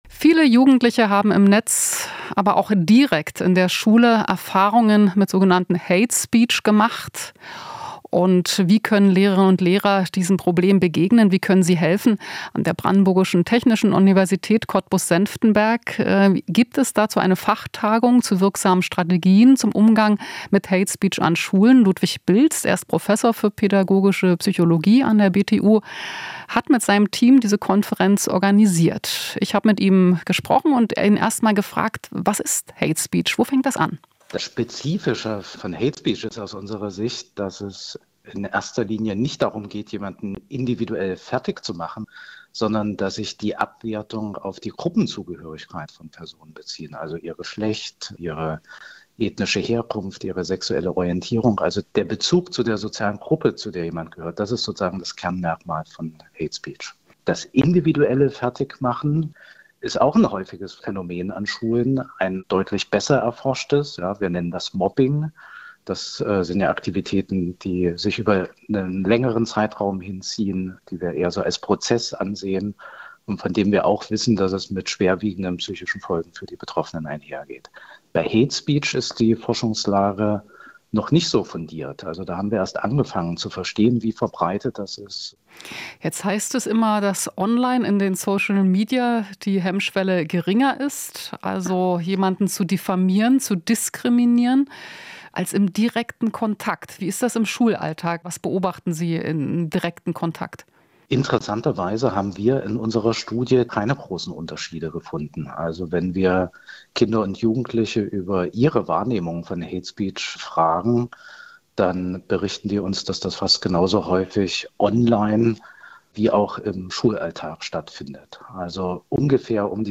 Interview - Schule ohne Hass: Was tun gegen Hatespeech?